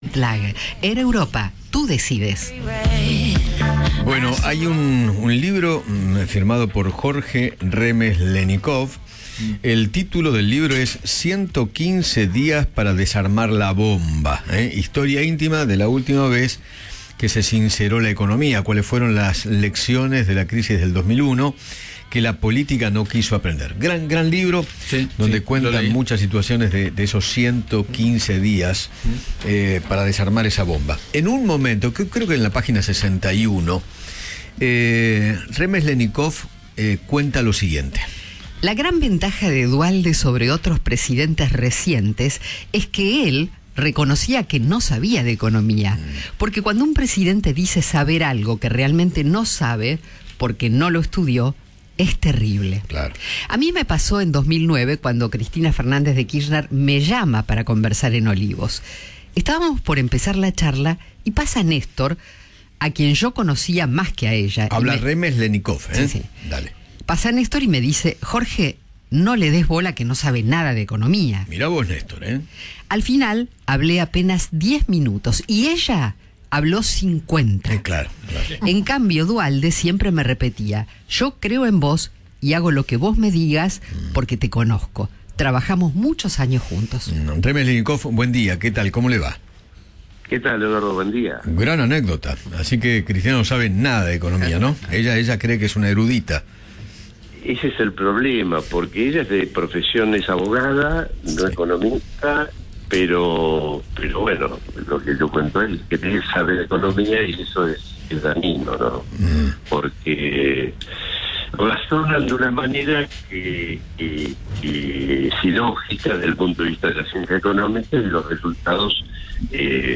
El economista Jorge Remes Lenicov dialogó con Eduardo Feinmann sobre su libro ‘115 días para desarmar la bomba’, donde cuenta una charla que mantuvo con Cristina Kirchner en 2009.